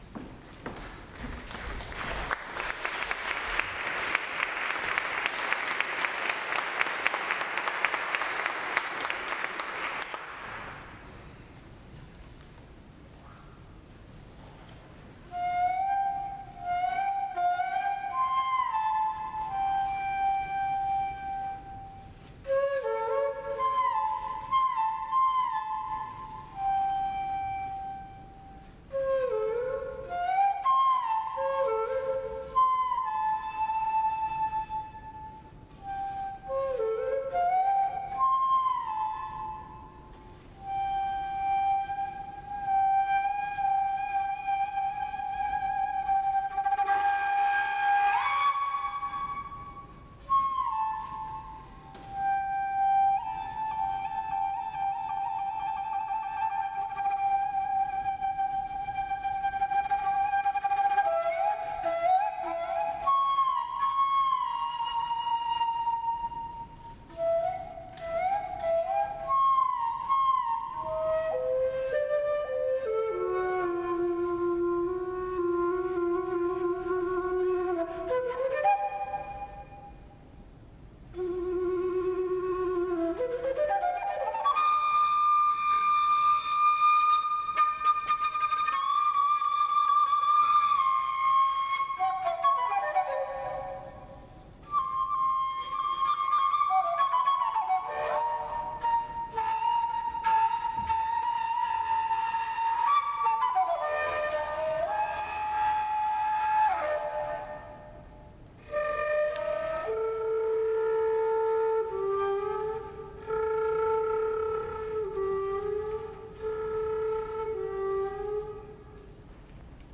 Premiere at Spoleto Festival USA 2012